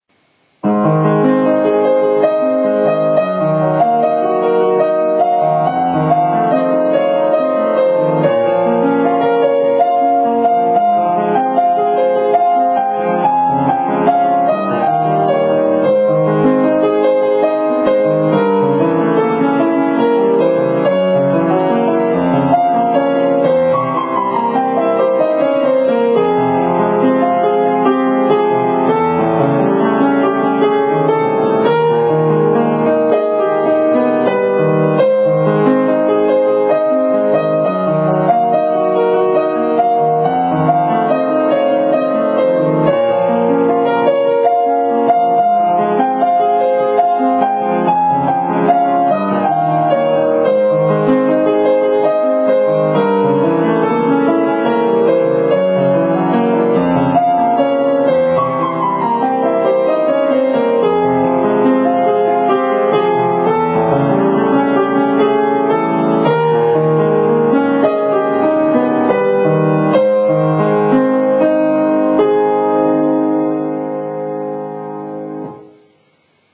ピアノのしらべ：シューマン作曲「謝肉祭」より No.12「ショパン」